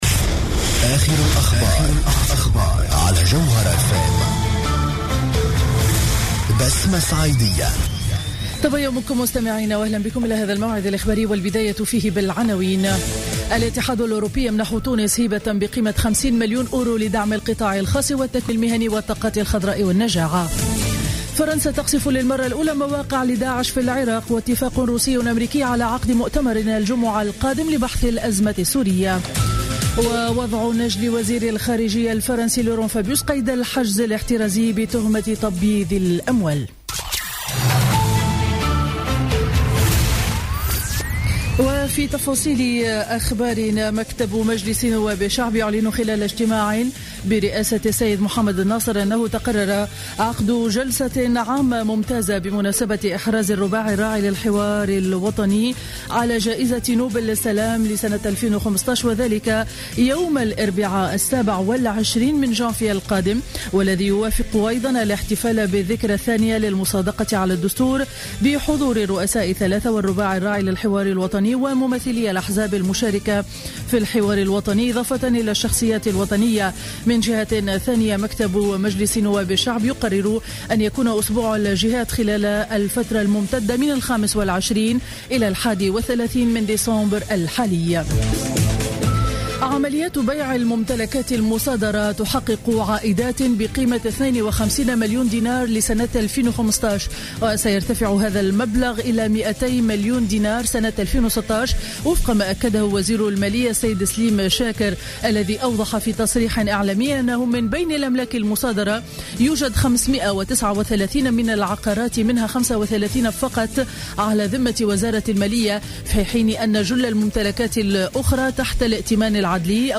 نشرة أخبار السابعة صباحا ليوم الأربعاء 16 ديسمبر 2015